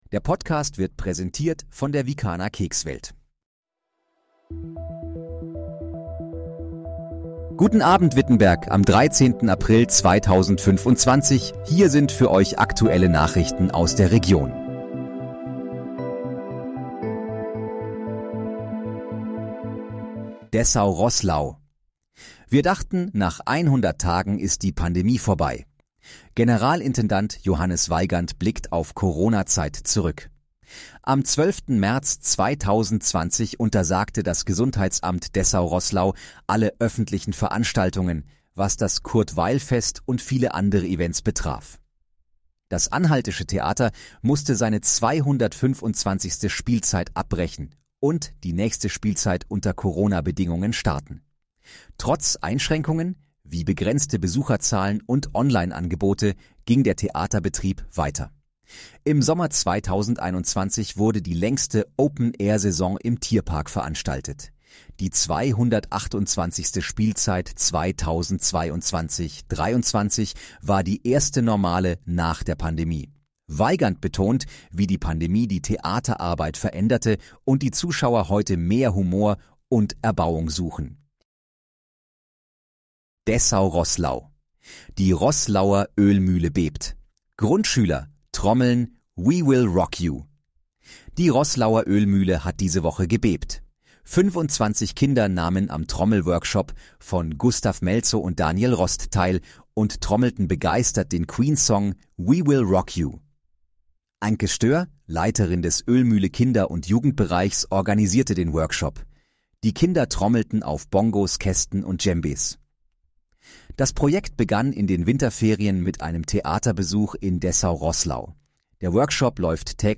Guten Abend, Wittenberg: Aktuelle Nachrichten vom 13.04.2025, erstellt mit KI-Unterstützung
Nachrichten